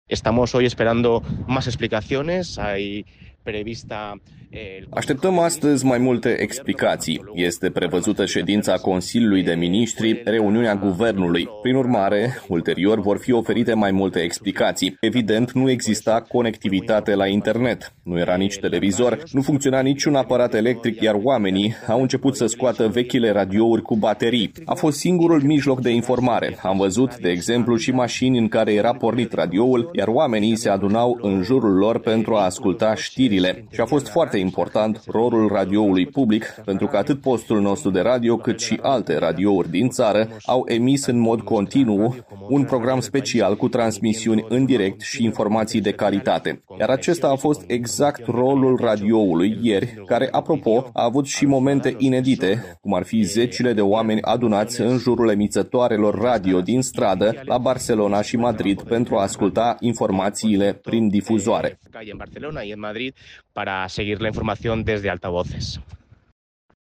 Într-o declarație oferită postului nostru de radio